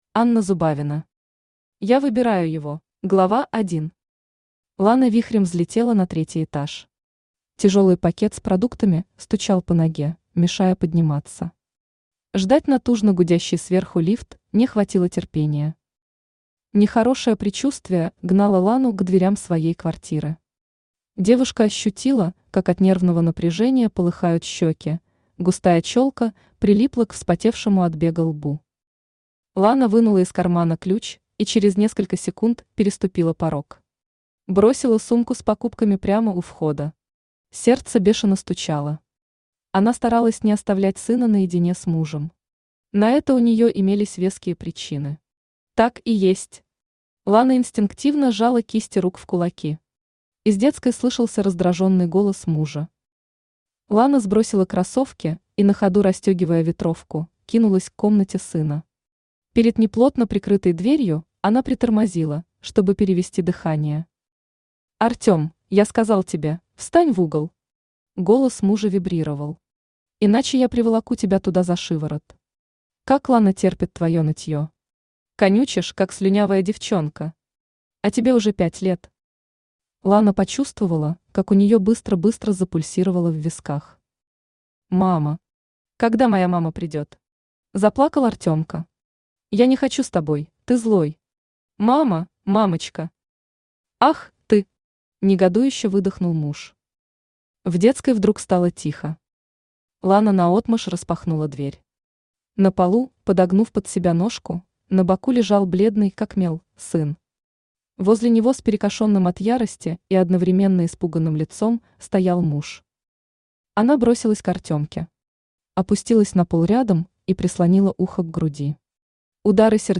Аудиокнига Я выбираю его | Библиотека аудиокниг
Aудиокнига Я выбираю его Автор Анна Зубавина Читает аудиокнигу Авточтец ЛитРес.